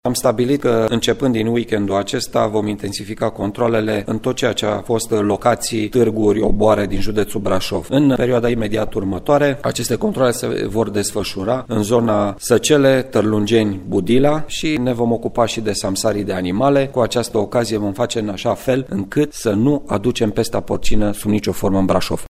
Astfel, echipe mixte de control vor viza zonele în care se organizează, de regulă, târguri pentru vânzarea sau cumpărarea de animale, ne-a declarat prefectul judeţului Braşov, Marian Rasaliu: